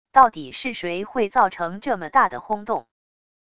到底是谁会造成这么大的轰动wav音频生成系统WAV Audio Player